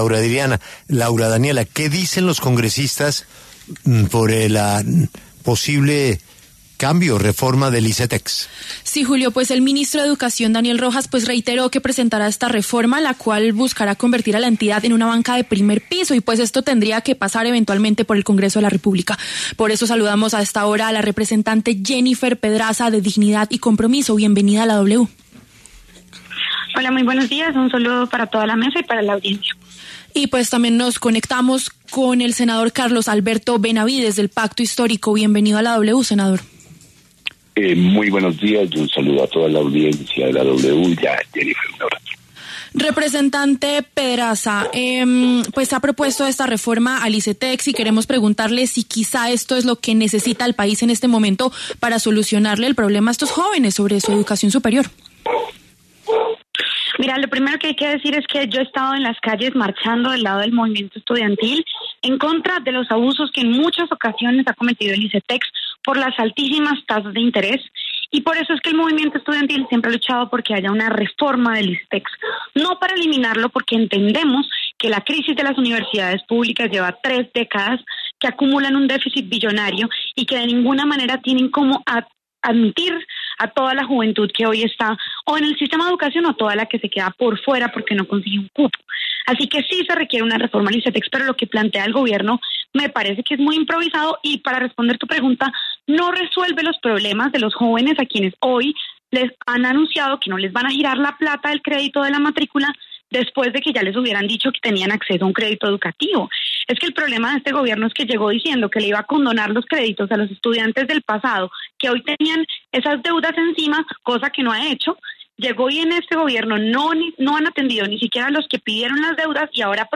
Los congresistas Jennifer Pedraza, de Dignidad y Compromiso, y Alberto Benavides, del Pacto Histórico, debatieron en los micrófonos de La W sobre la reforma al Icetex anunciada por el ministro de Educación Daniel Rojas.